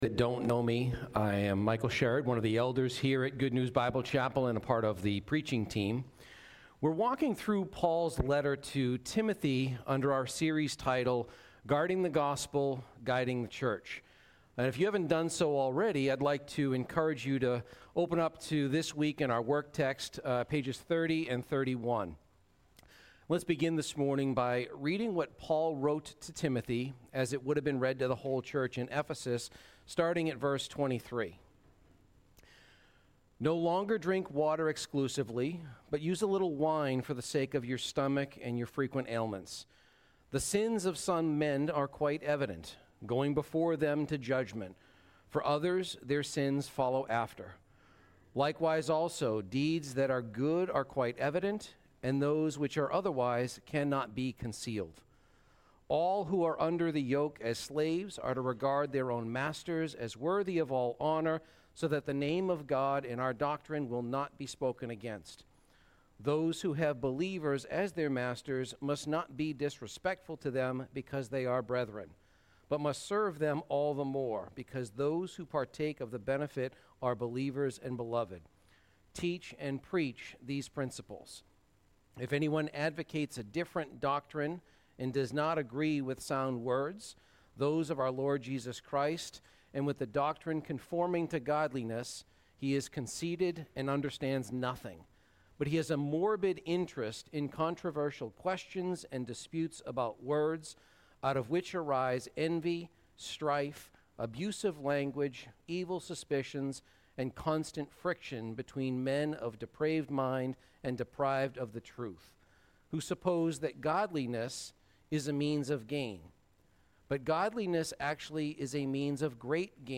Series: 1 & 2 Timothy - Guarding the Gospel - Guiding the Church Service Type: Celebration & Growth